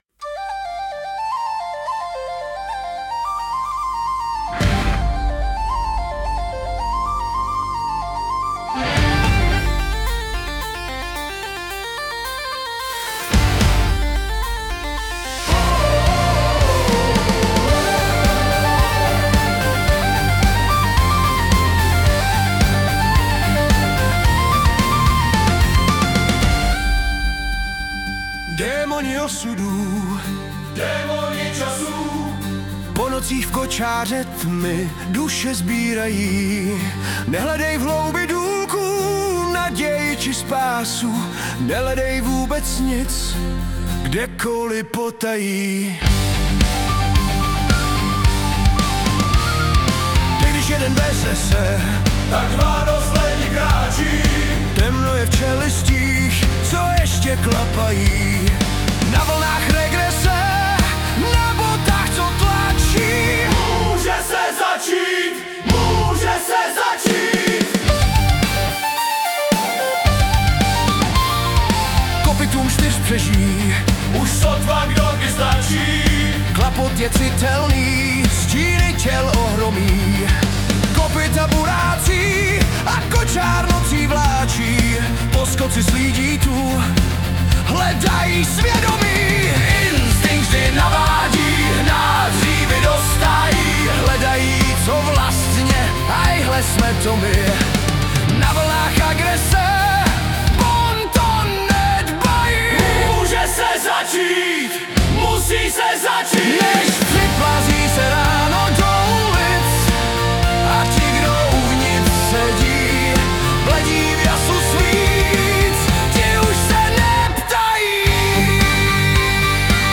Anotace: přetextovaná a zhudebněná